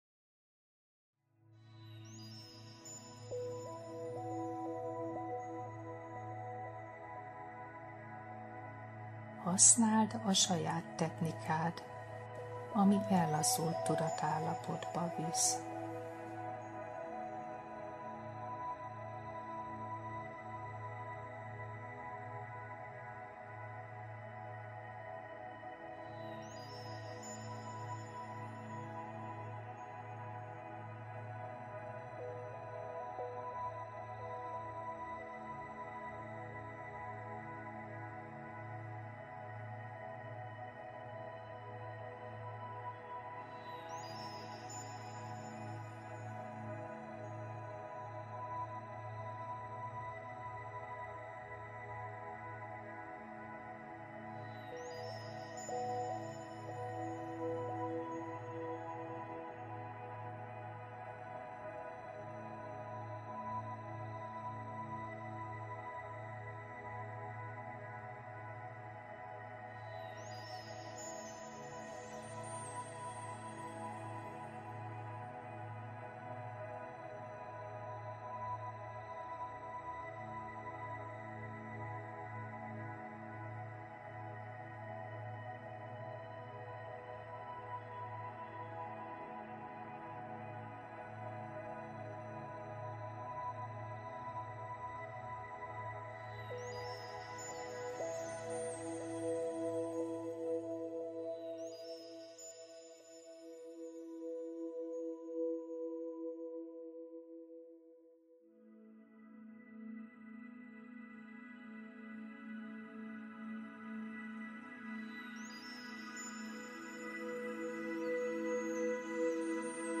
Meditáció a túszok kiszabadításáért - Hungarian guided audio
meditacio_a_tuszok_kiszabaditasaert_-_hungarian_guided_audio.mp3